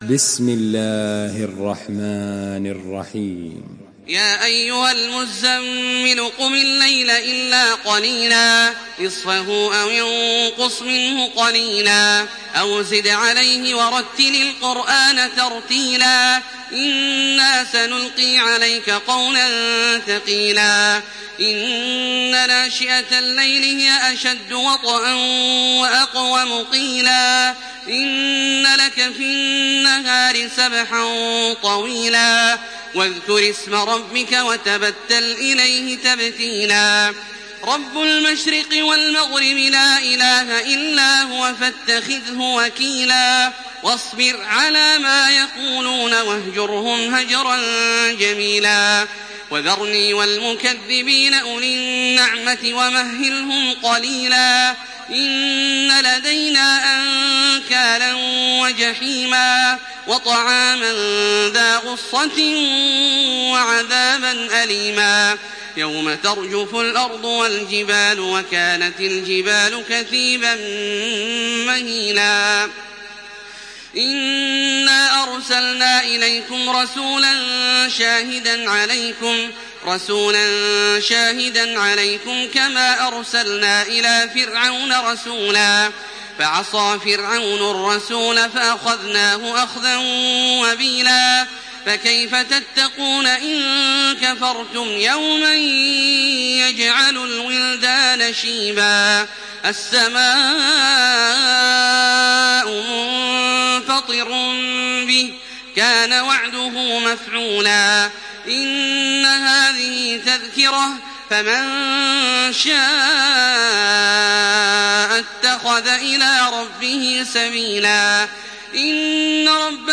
Surah Müzemmil MP3 by Makkah Taraweeh 1428 in Hafs An Asim narration.
Murattal